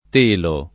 [ t % lo ]